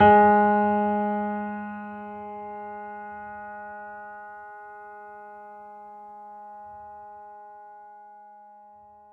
piano-sounds-dev
Vintage_Upright
gs2.mp3